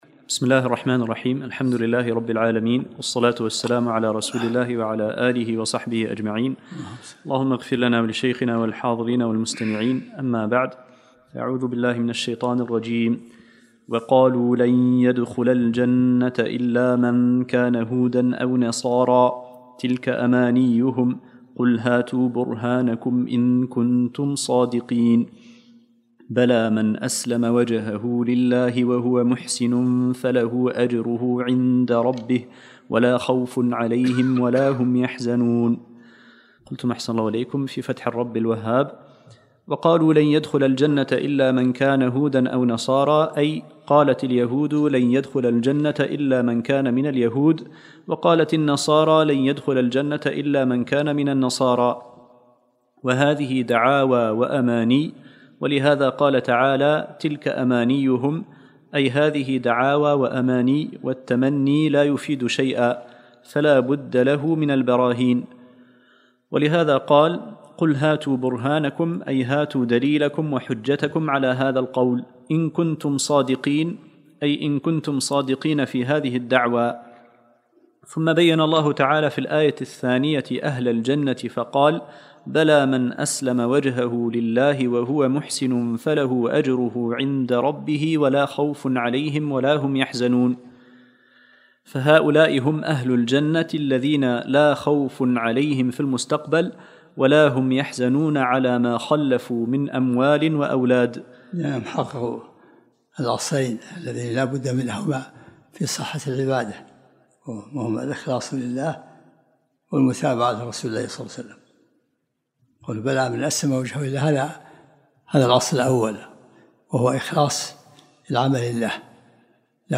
الدرس الثامن من سورة البقرة